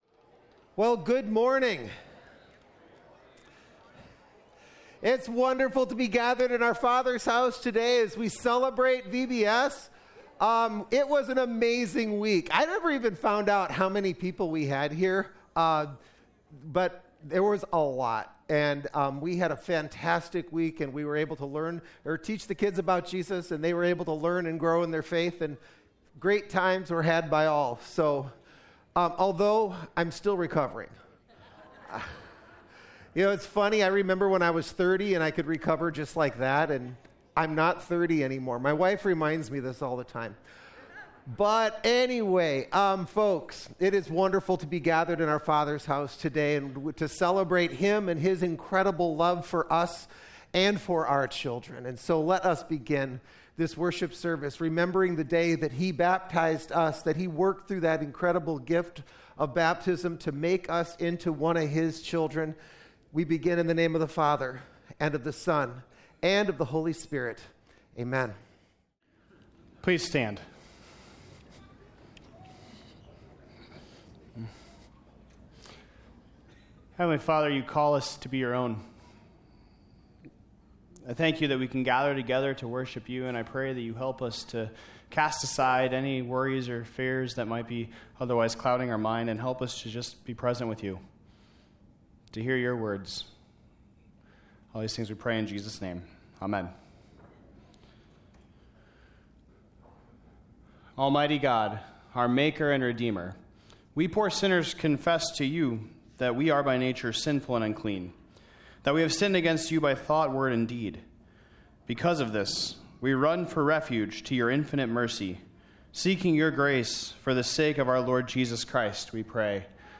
2025-June-8-Complete-Service.mp3